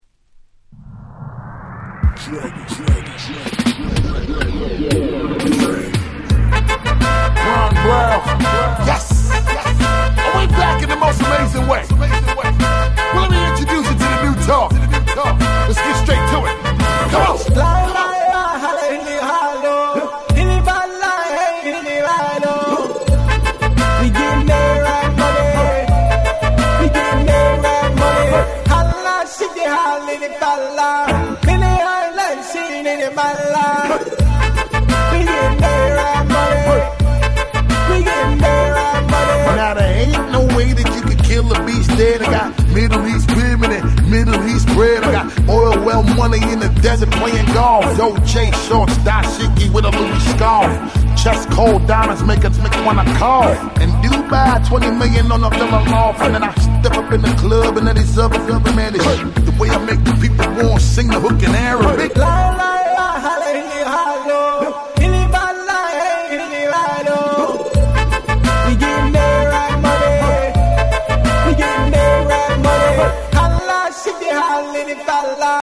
08' Big Hit Hip Hop !!!!!